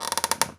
chair_frame_metal_creak_squeak_04.wav